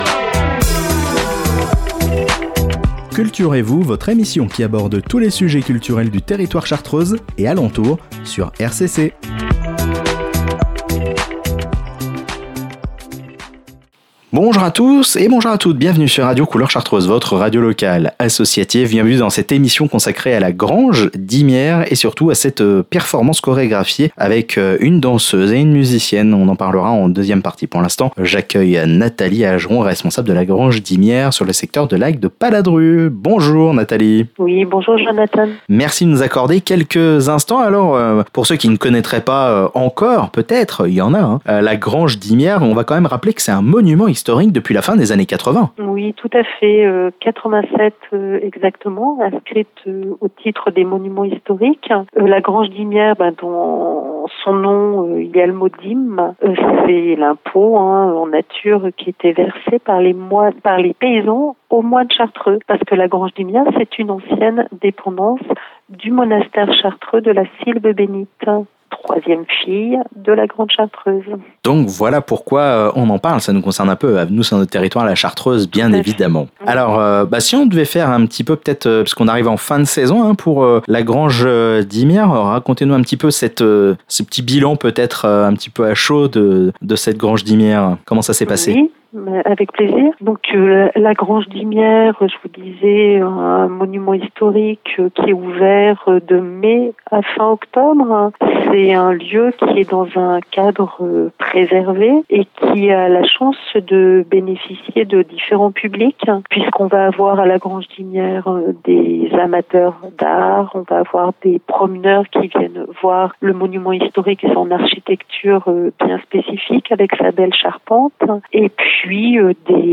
EMISSION AVEC LA GRANGE DIMIERE